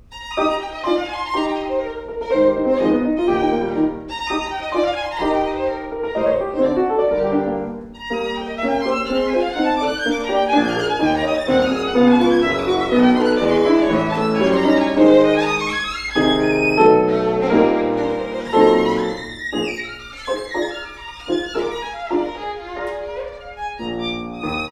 Violin Bow
• Performance at Kleinert/James Center for the Arts (Woodstock Byrdcliffe Guild) September 26, 2015
violin
piano